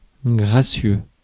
Ääntäminen
France: IPA: [ɡʁa.sjø]